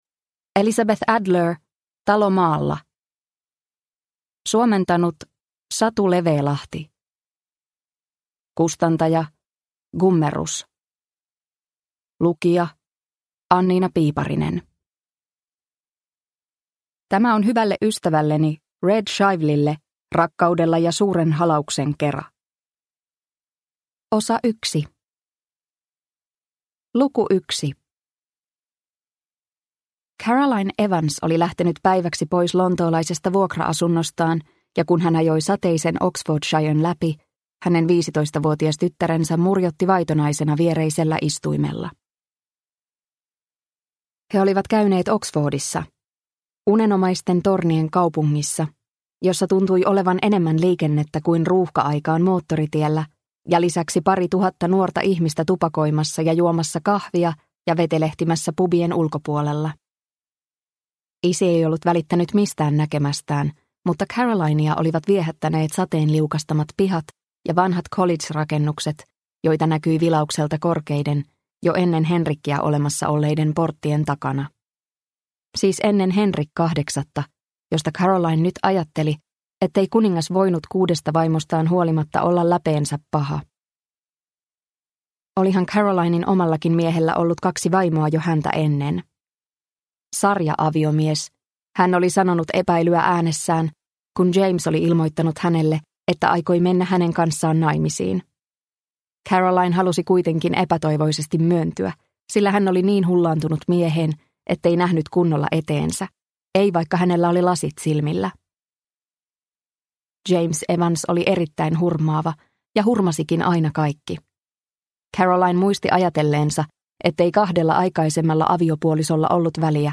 Talo maalla – Ljudbok – Laddas ner